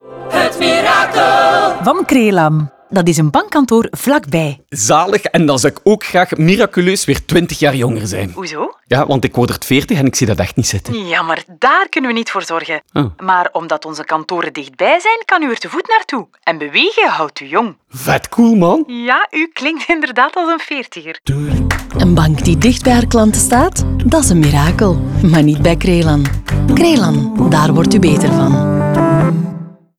Na de week van de lancering volgen twee klassiekere spots van 30 seconden.
Crelan-Mirakel-Radio-20j.wav